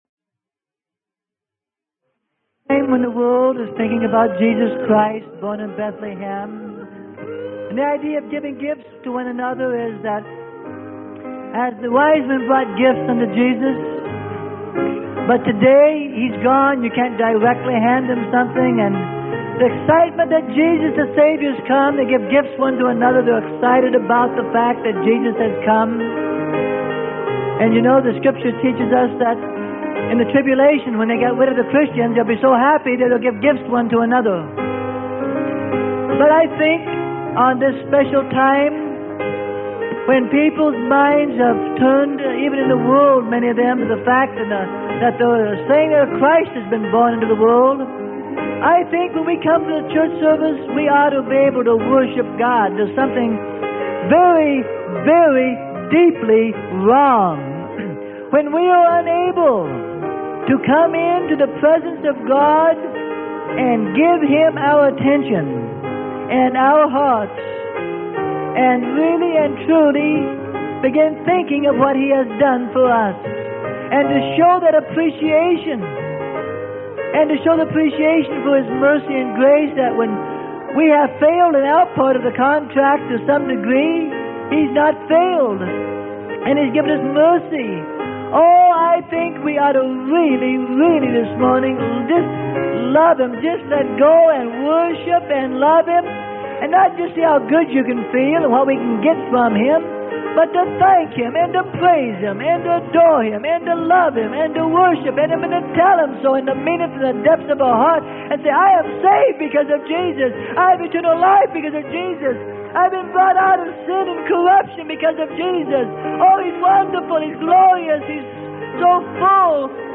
Sermon: Now the Birth of Christ Was On This Wise - Freely Given Online Library